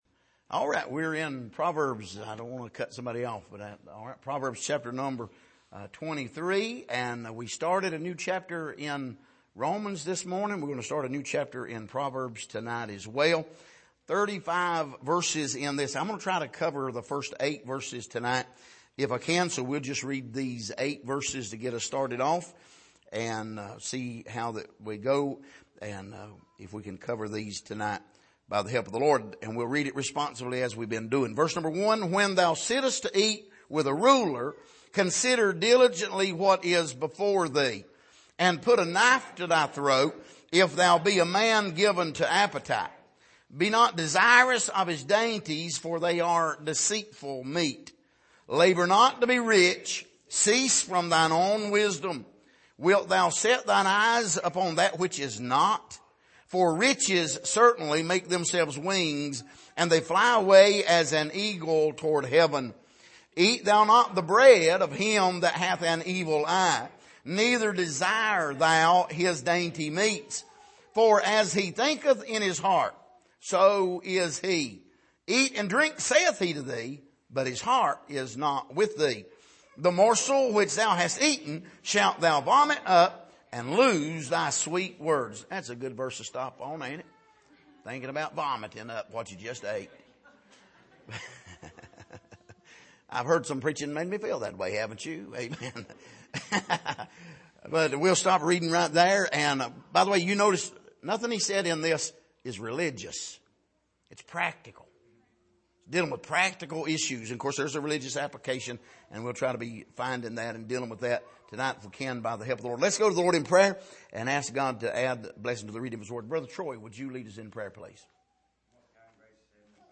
Passage: Proverbs 23:1-8 Service: Sunday Evening